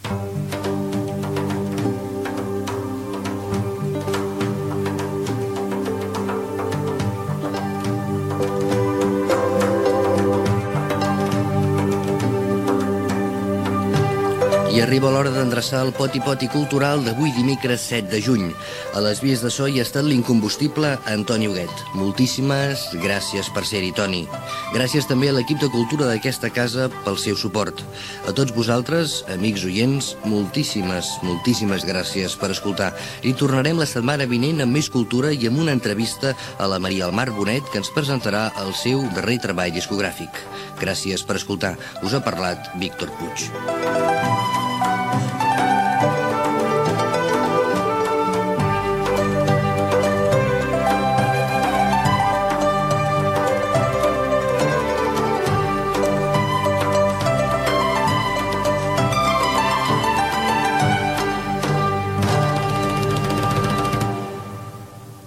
Banda FM